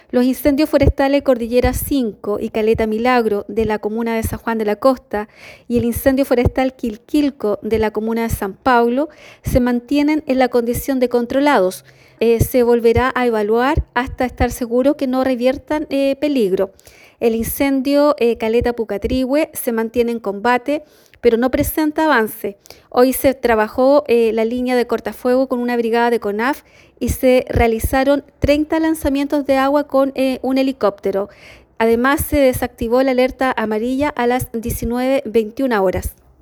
La Delegada Presidencial Provincial de Osorno, Claudia Pailalef, indicó que tanto el incendio forestal de San Juan de la Costa, como el de la comuna de San Pablo, se encuentran en la condición de controlados, siendo evaluados por lo equipos técnicos nuevamente durante esta mañana.